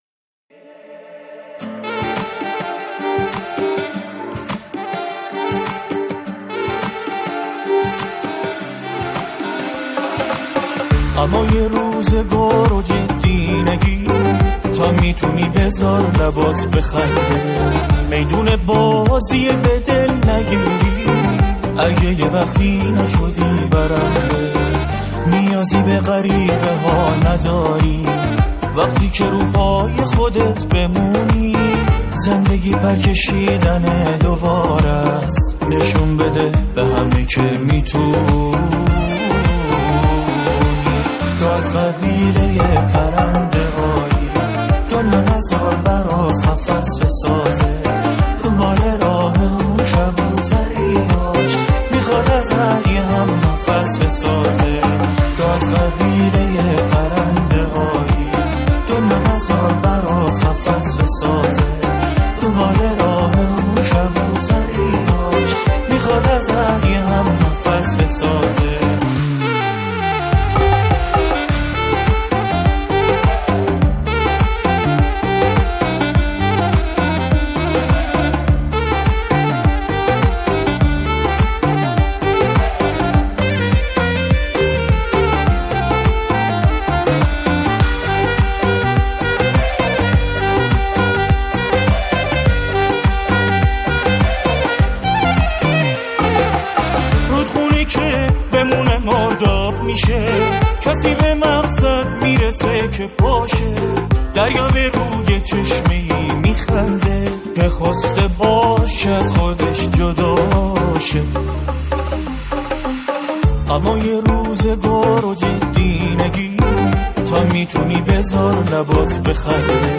سرودهای انگیزشی
همخوان زن